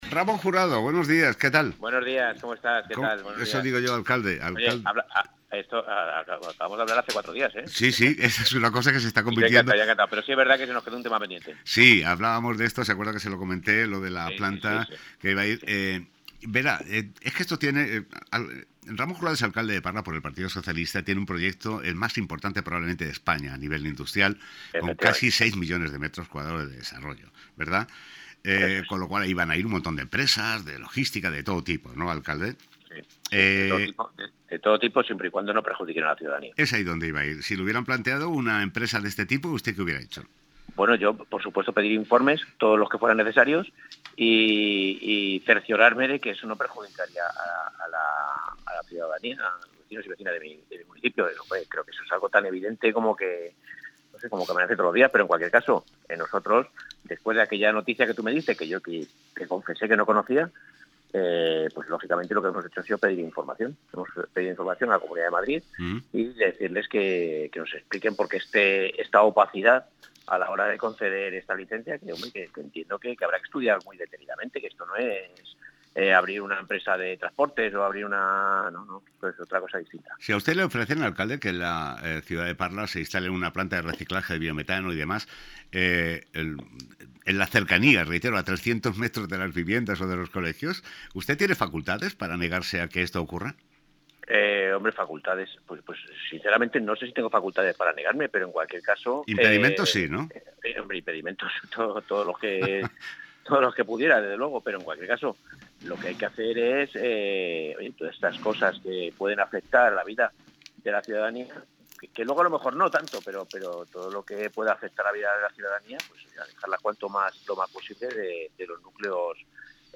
Entrevista al Alcalde de Parla sobre la planta de residuos de Cubas
Ramón Jurado, alcalde del PSOE en Parla, ha pasado esta mañana de lunes por los micrófonos de GloboFM para ofrecer una pequeña intervención sobre los hechos referentes a la planta de residuos que se prevé crear en el municipio de Cubas de la Sagra.
11-03_ENTREVISTA-RAMONJURADO.mp3